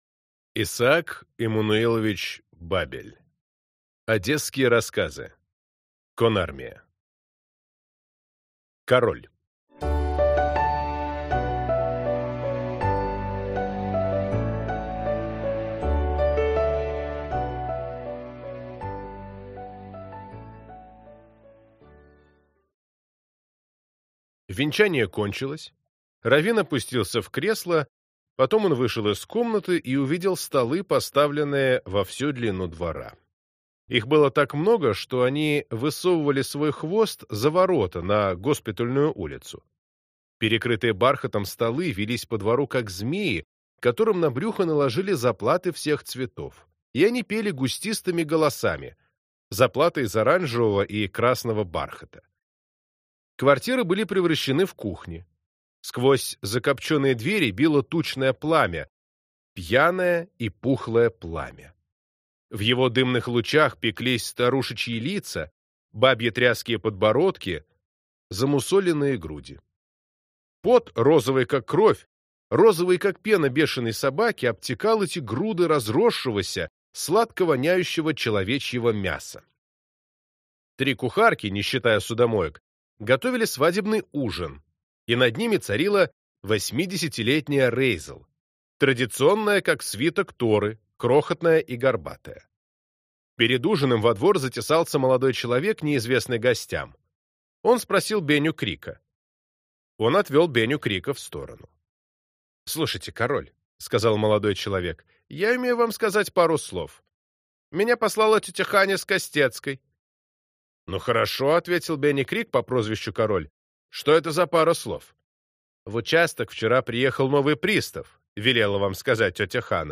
Аудиокнига Одесские рассказы. Конармия | Библиотека аудиокниг